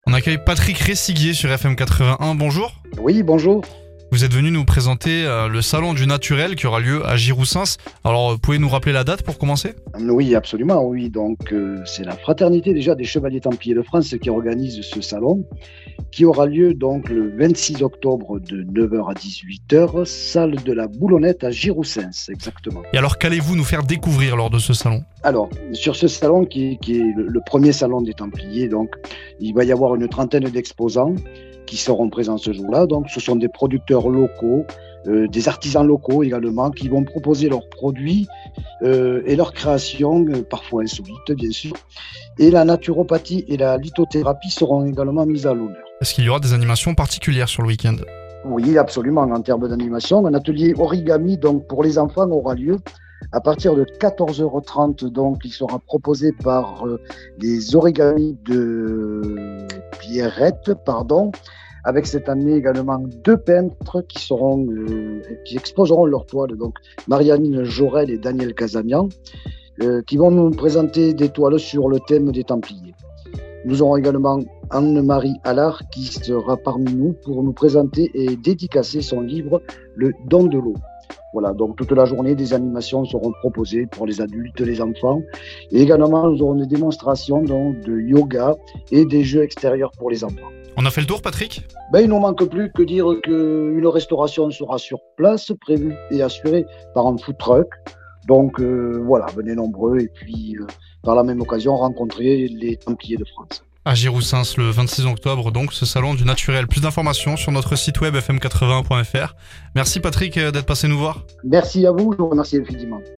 Dans la Vie locale de FM81, nous recevrons un des organisateurs pour dévoiler les temps forts du salon, présenter les exposants, et inviter les auditeurs à venir rencontrer ces talents locaux engagés autour du naturel.